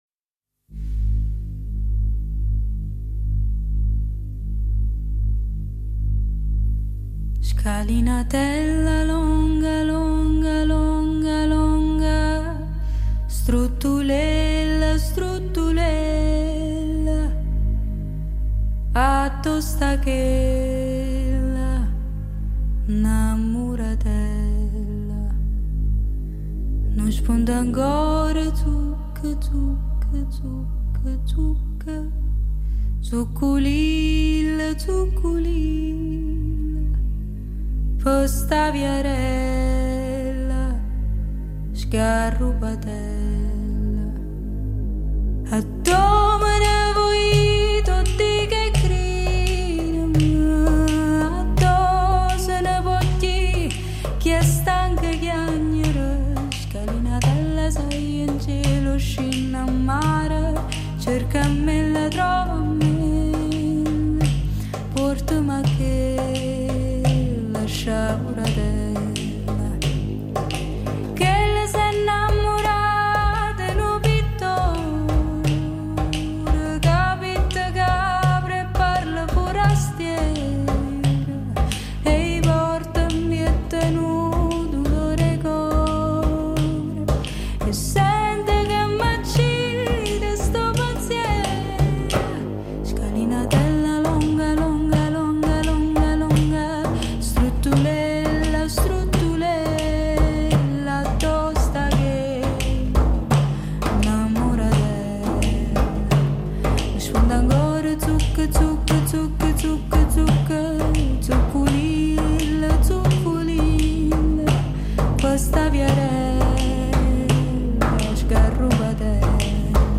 cantautrice e contrabbassista salernitana
Otto tracce intime e “soffuse”
un sound dal gusto un po’ retrò e atmosfere jazz